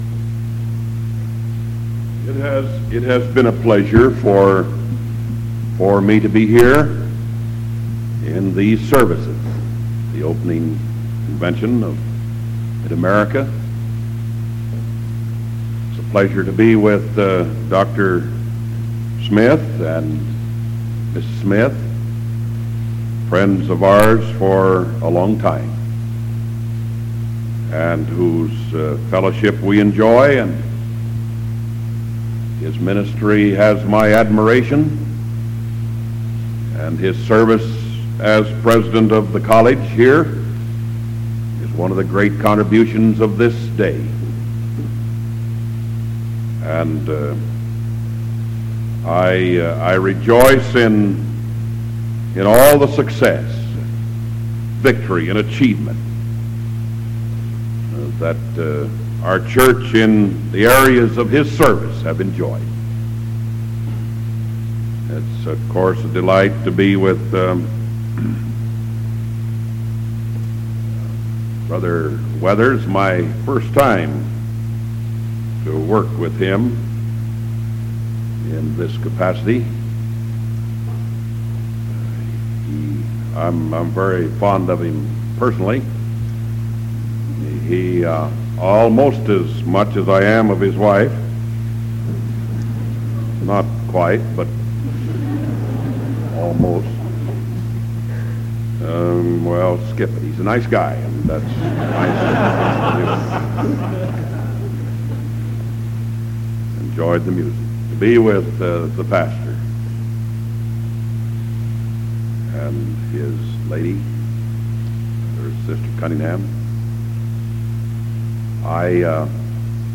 Sermon September 9th 1973 PM